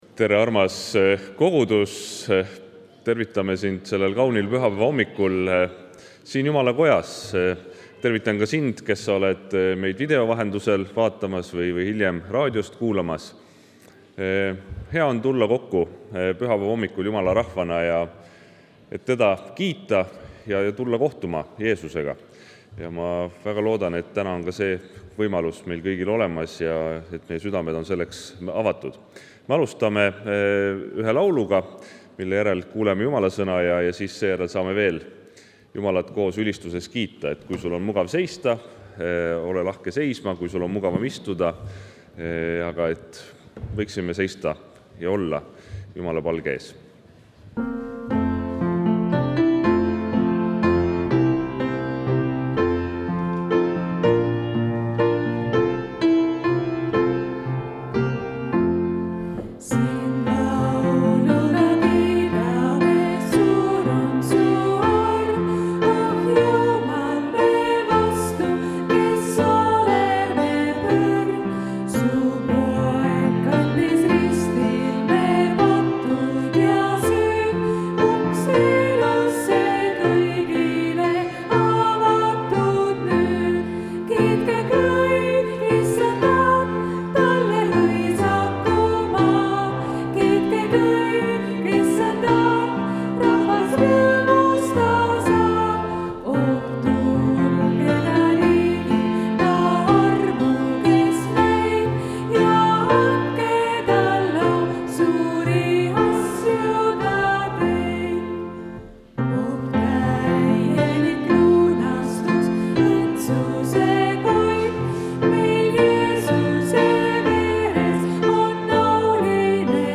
Pühakirja lugemine
Jutlus